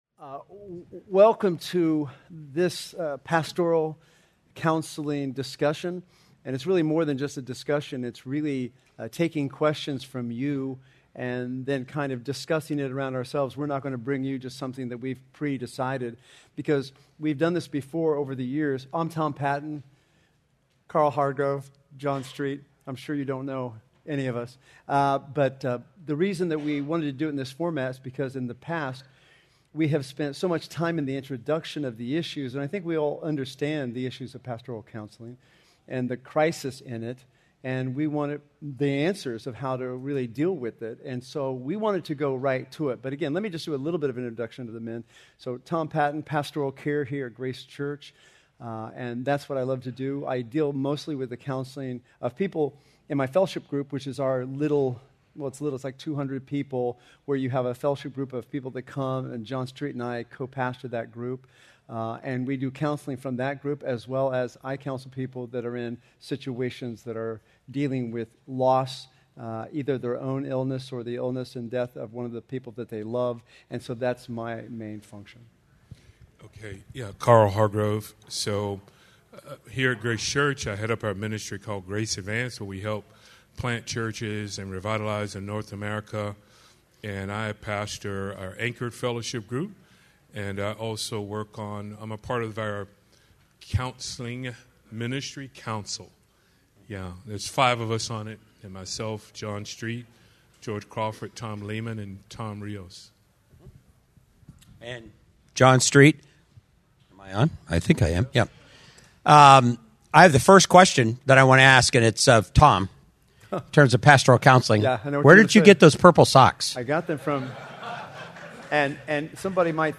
Pastoral Counseling & Care Panel Discussion